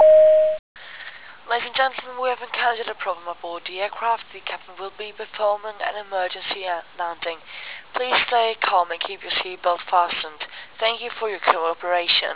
A350-family/Sounds/announce/emergency.wav at c04c12d4ba9d76e9a9a030044a14186d8c624750